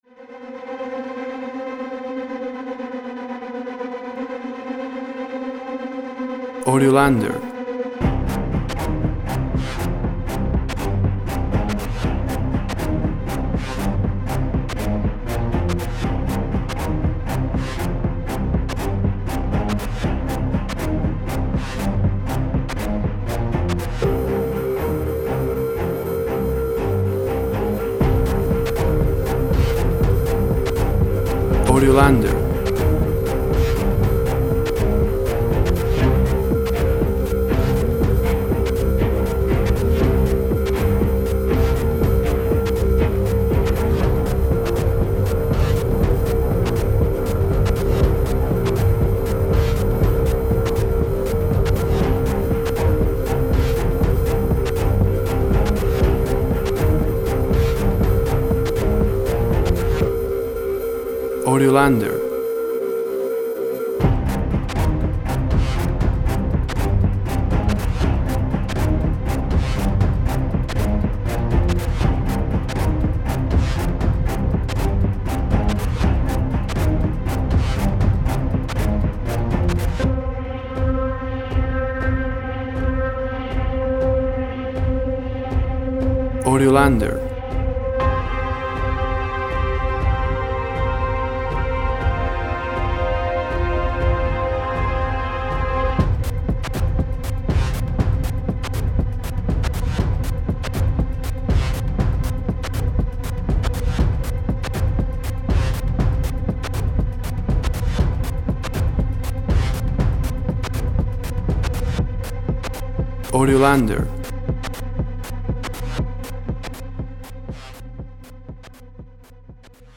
Action music set in a flight crisis.
Tempo (BPM) 120